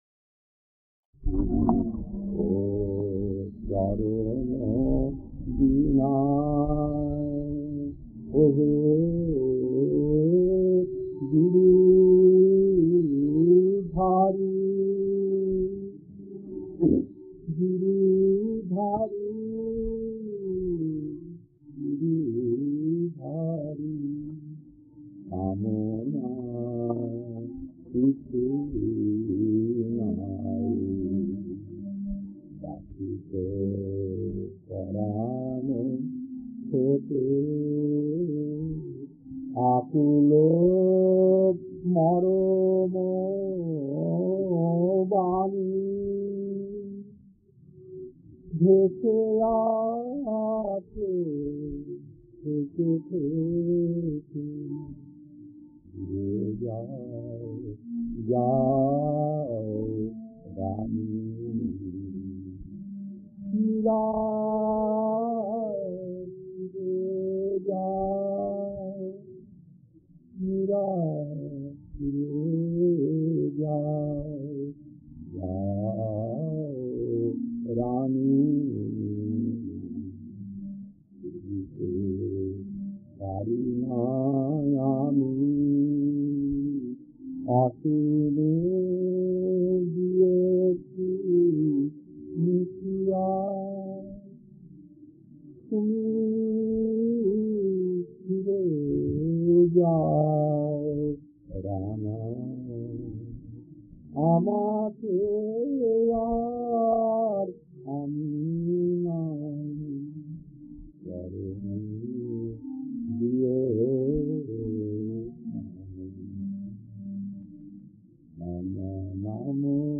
Kirtan Kirtan 9 Charane Diyo Namo Namo Narayana Aami Aapan Janare ke diyeche thakur Rupjadi tov Baachan Baanchi Mama Bhajle Sita Ram Ragupati Raghav Jagat Janani Tumi Bhaje Radhe Krishna Jaya Raahe Raadhe